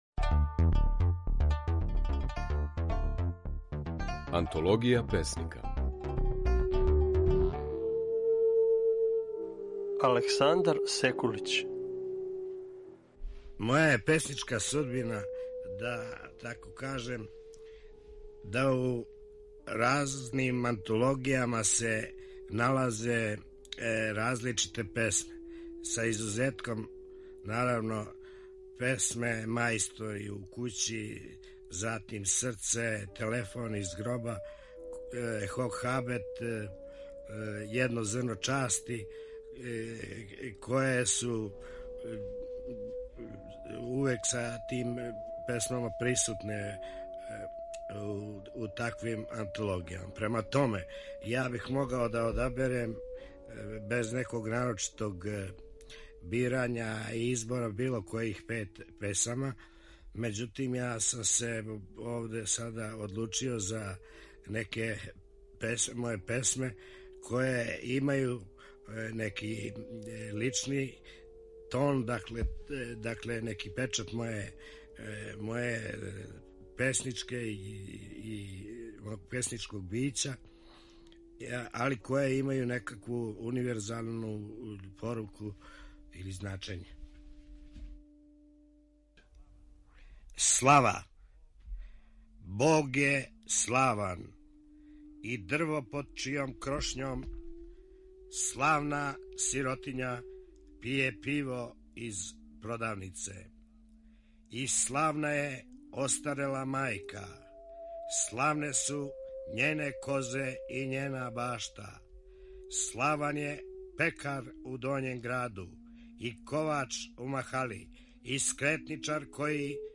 Данас у Антологији песника, можете чути како је своје стихове казивао песник Александар Секулић (1937–2009).
Емитујемо снимке на којима своје стихове говоре наши познати песници